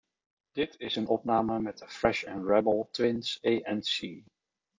Hieronder staan vier opnames met in-ear oordopjes.